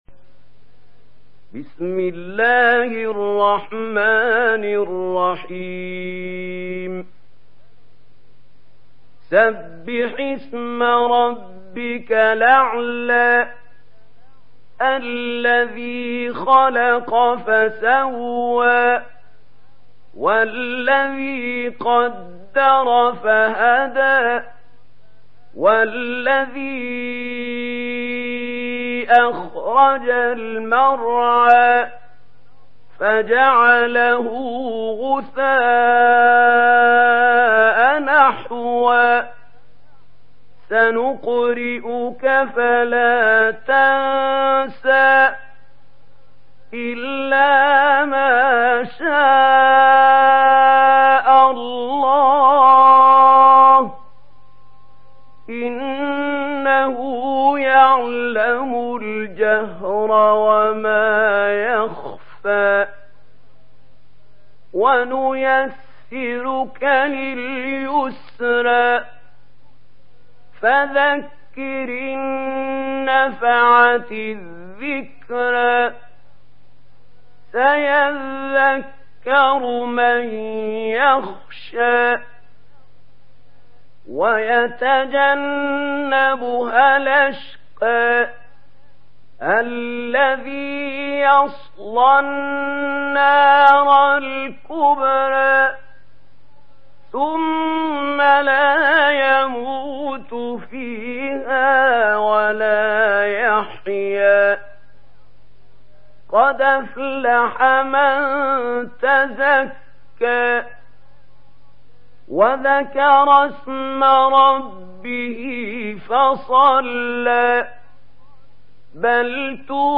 Ala Suresi mp3 İndir Mahmoud Khalil Al Hussary (Riwayat Warsh)